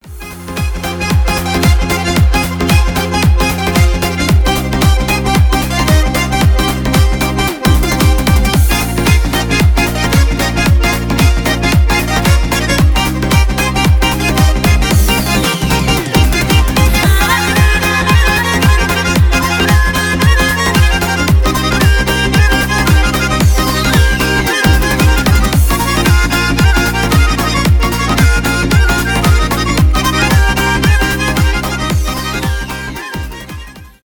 танцевальные
без слов
лезгинка